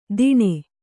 ♪ diṇe